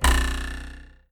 arrow_miss.ogg